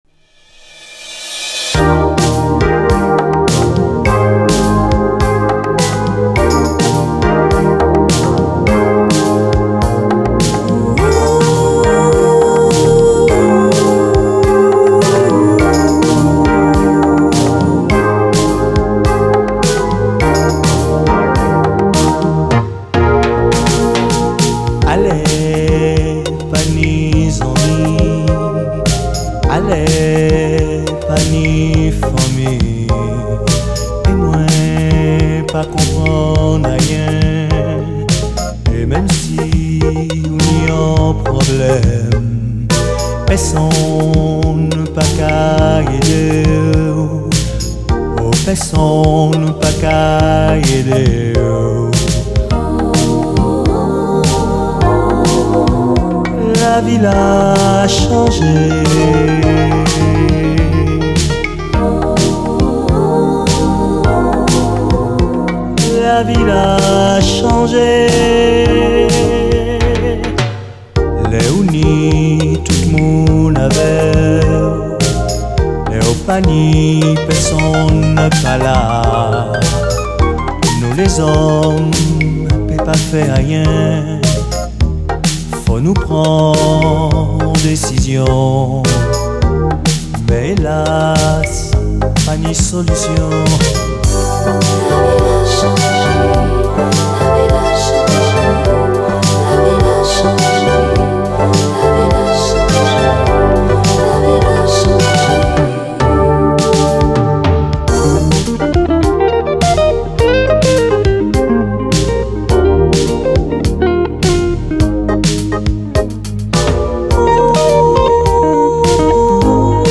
CARIBBEAN
最高な清涼ドリーミー・メロウ・ズーク・ラブ！
ピアノ＆ギターのジャジーなプレイも非常にお洒落です。